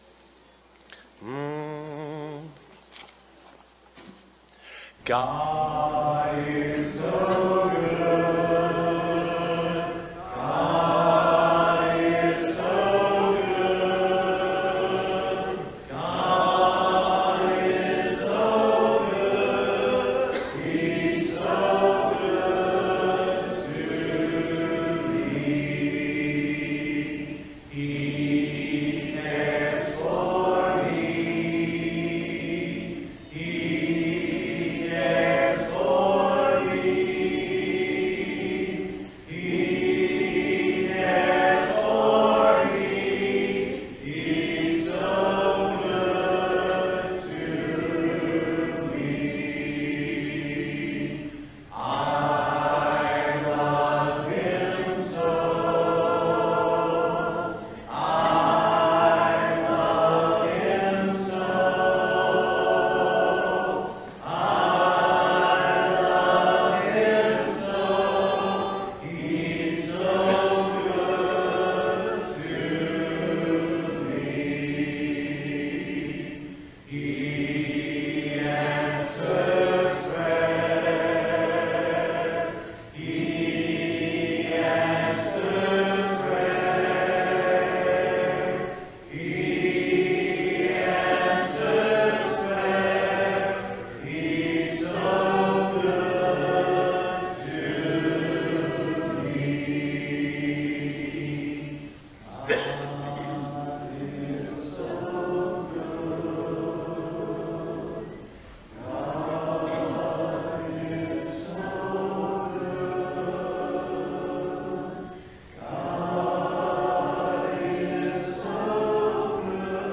Matthew 15:8, English Standard Version Series: Sunday PM Service